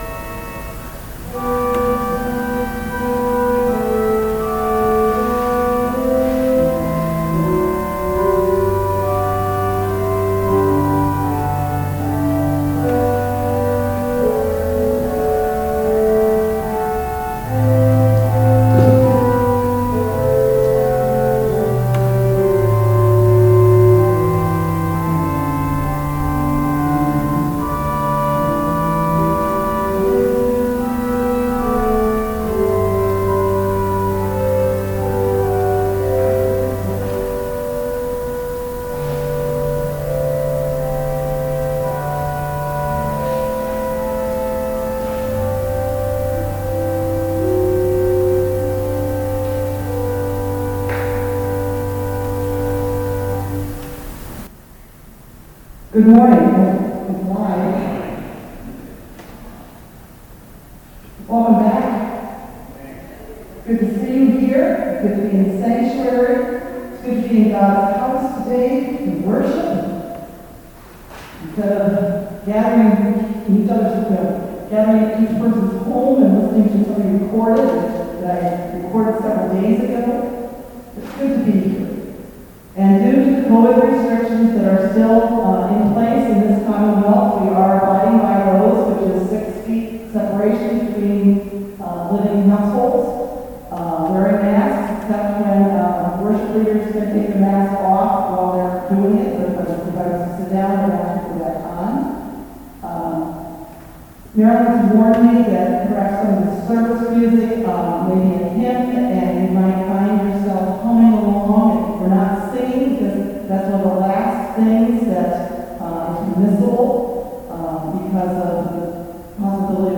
Worship Service May 23, 2021 | First Baptist Church, Malden, Massachusetts